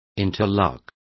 Complete with pronunciation of the translation of interlocking.